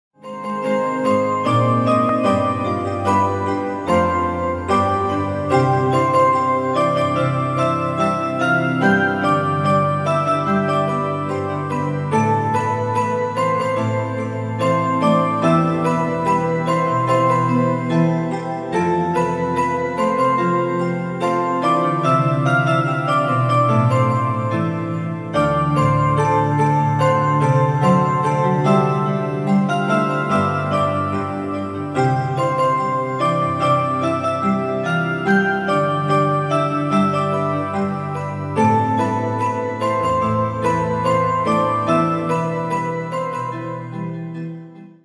せつなさと愛しさがあふれるハートウォーミングなサウンドで、ひとときの安らぎと小さな春をお届けします―。